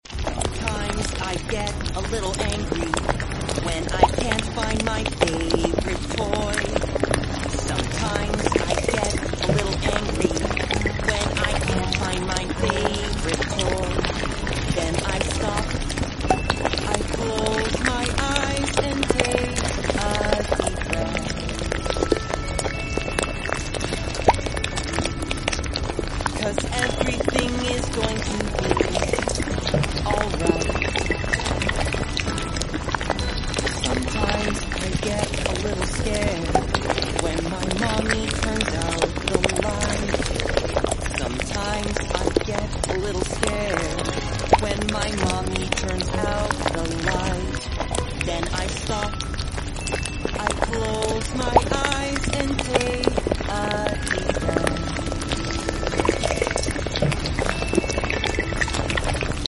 new cafe sounds sound effects free download